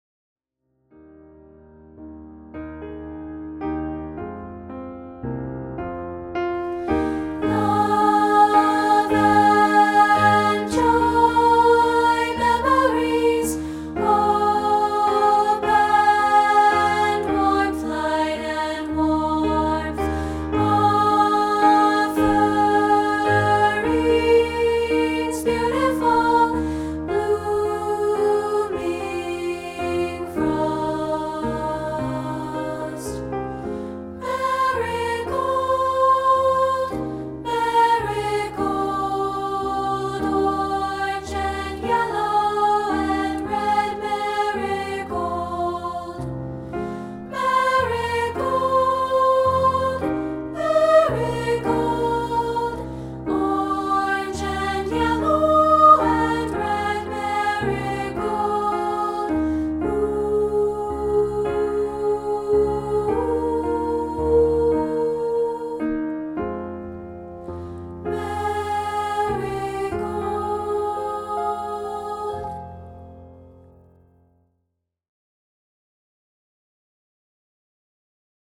This is a rehearsal track of part 3, isolated.